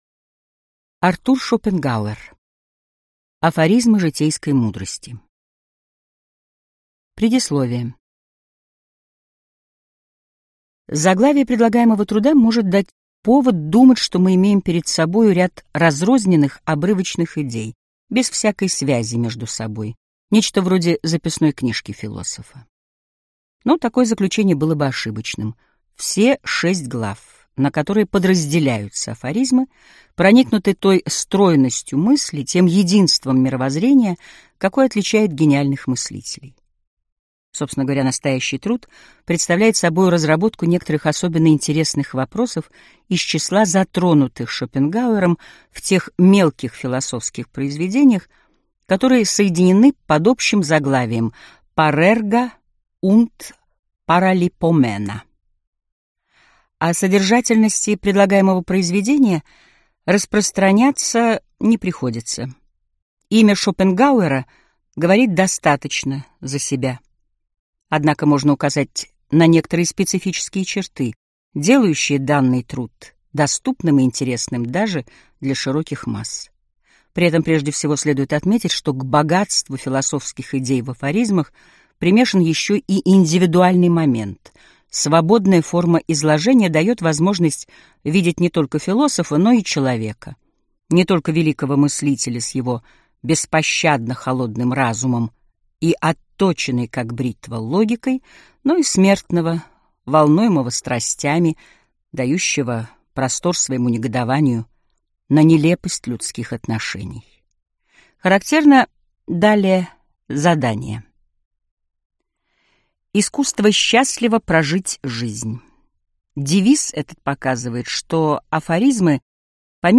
Аудиокнига Афоризмы житейской мудрости | Библиотека аудиокниг